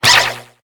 Cri de Voltoutou dans Pokémon HOME.